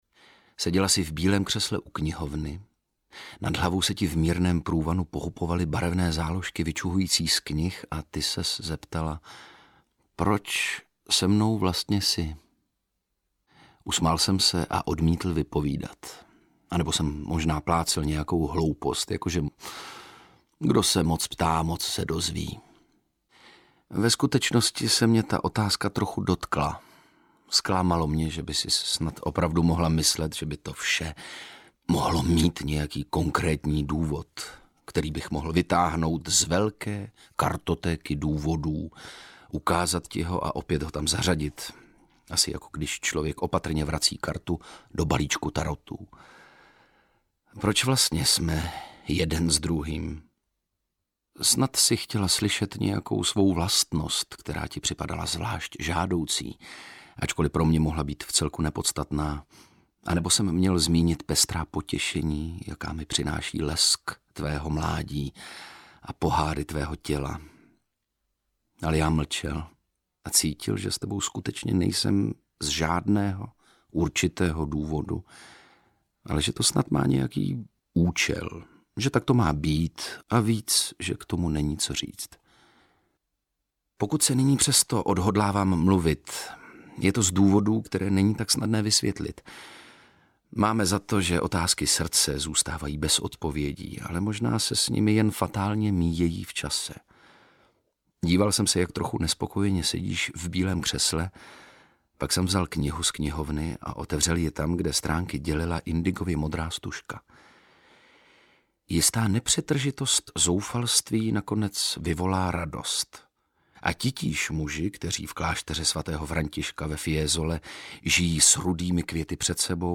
Audiokniha
Čte: Ondřej Brousek